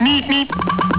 meep.wav